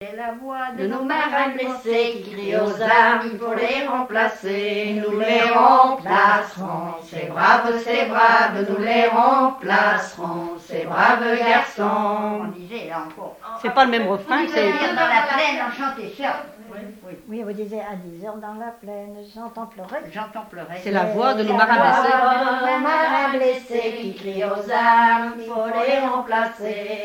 Fonction d'après l'analyste gestuel : à marcher ;
Genre énumérative
Témoignages sur la pêche, accordéon, et chansons traditionnelles